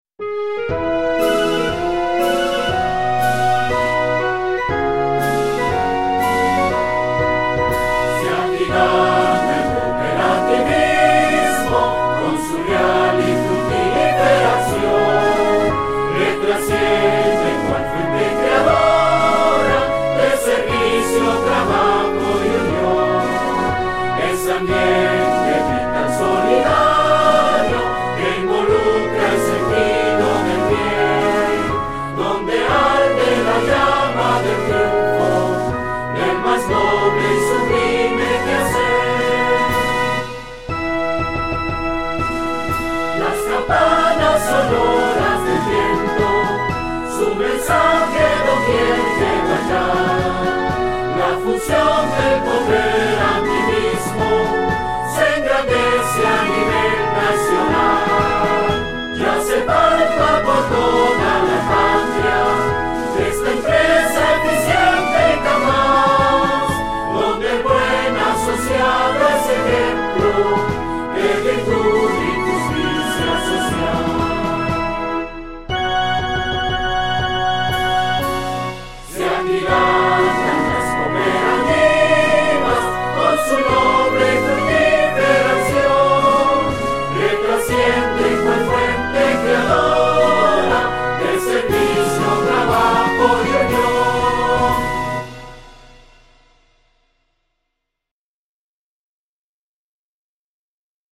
Creado en 1985 mediante un concurso convocado por UNACOOP R.L. La primera versión la grabó la orquesta y coro del Conservatorio Castella.